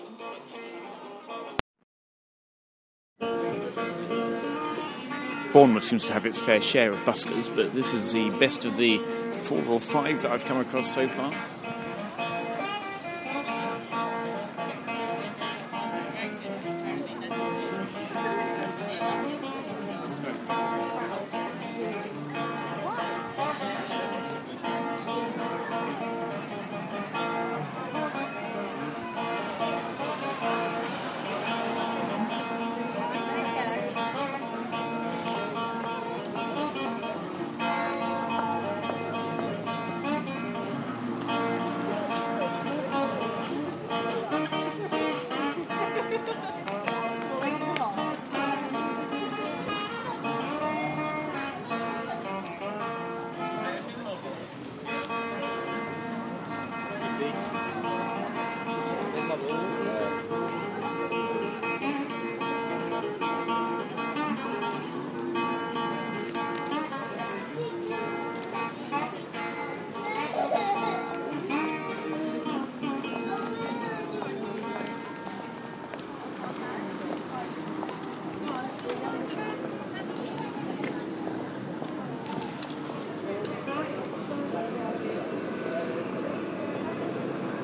Bluegrass busker in Bournemouth April 2015